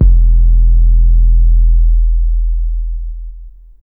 808 RAP K3.wav